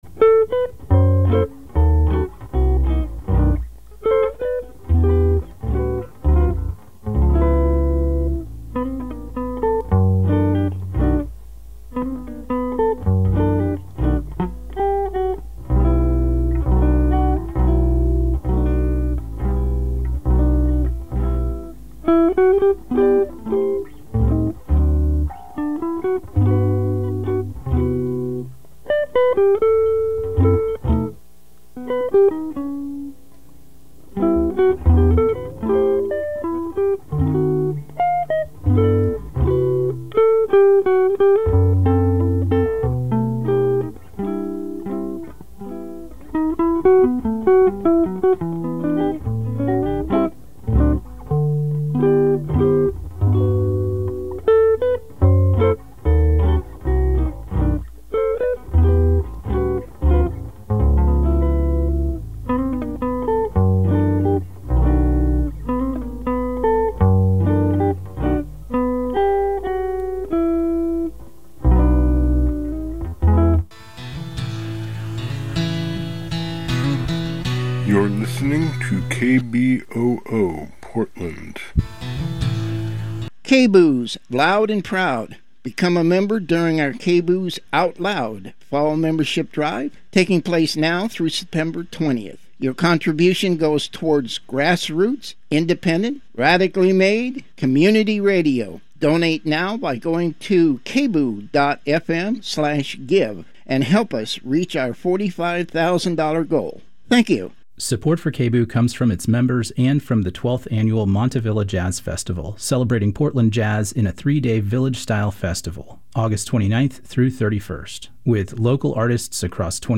We'll also talk with Oscar-nominated animator Bill Plympton, who is coming to Portland to screen two new films at the Clinton Street Theater: the Oregon-themed feature film Slide and the comical animated short Duckville. play pause mute unmute KBOO